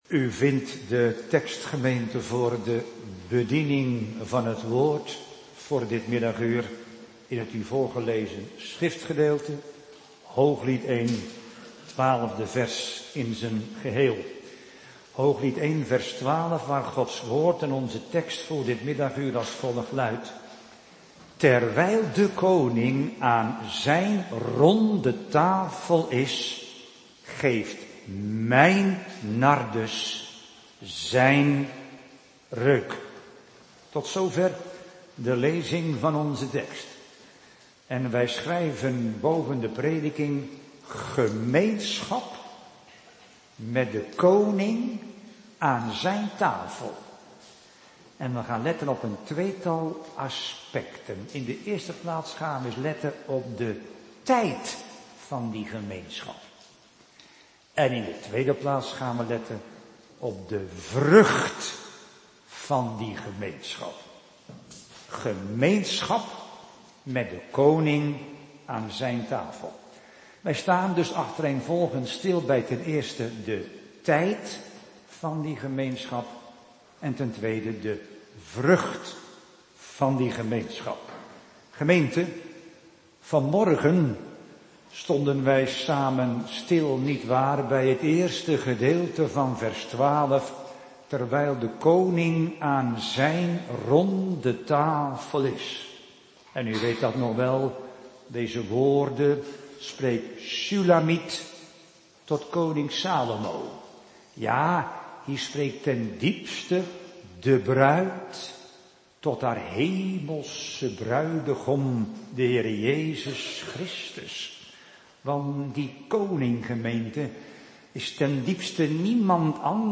Soort Dienst: Nabetrachting Heilig Avondmaal